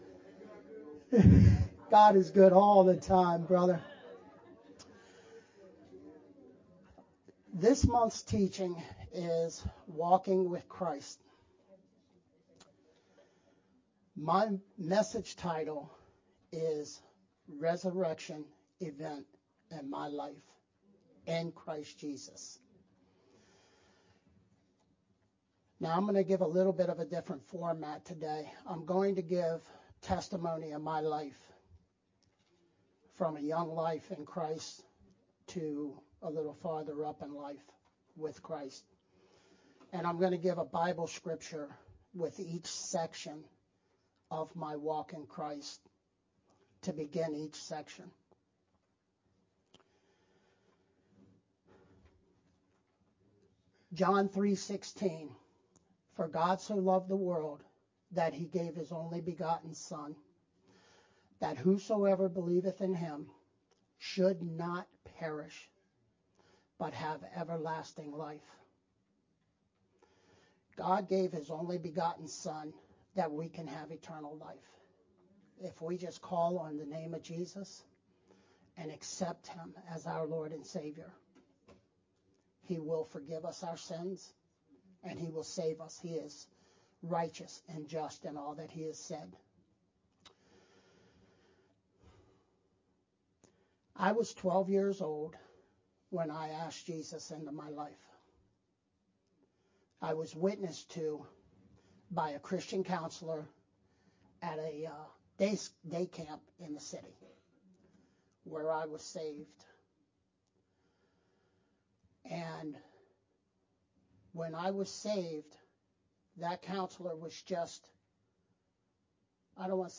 Nov-19th-Sermon-only_Converted-CD.mp3